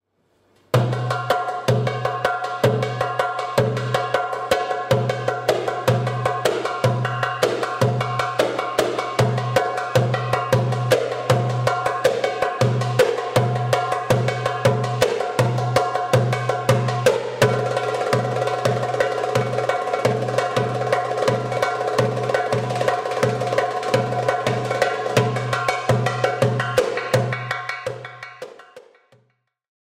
Traditional Tunisian Instrument
Darbuka
Audio file of the Darbuka
Sound-of-the-Darbuka.wav